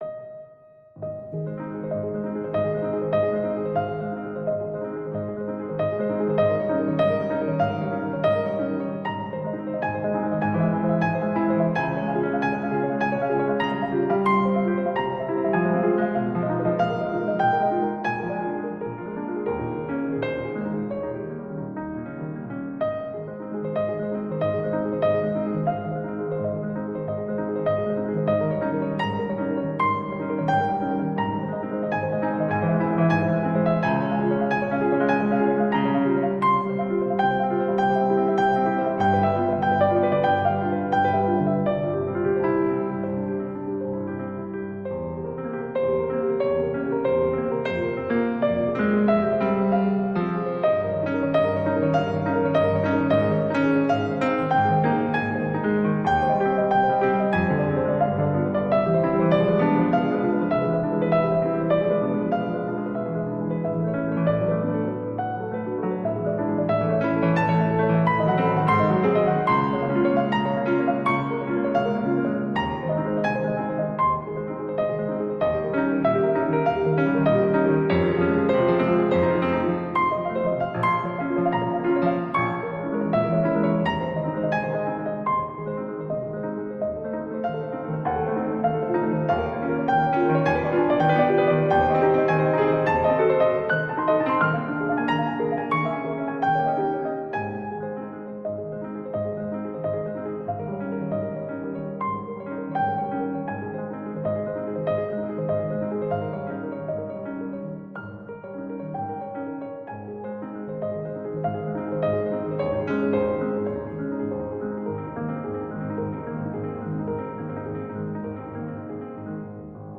magnifiquement réorchestrés et tous libres de droit.